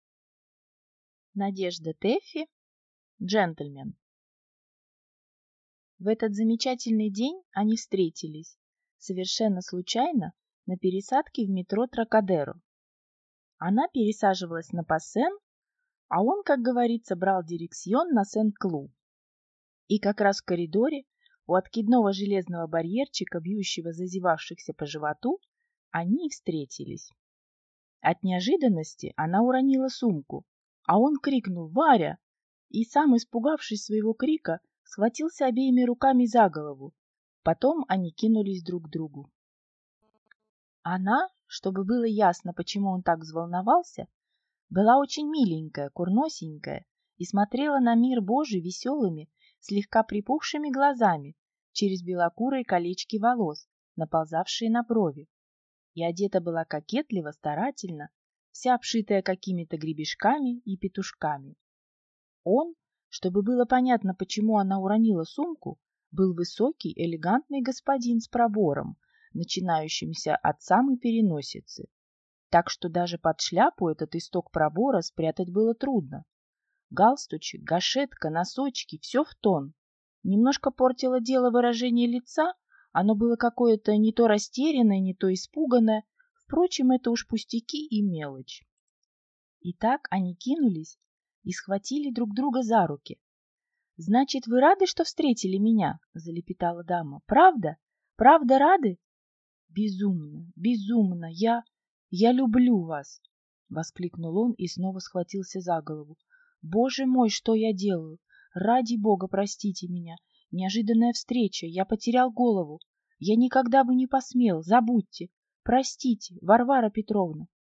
Аудиокнига Джентльмен | Библиотека аудиокниг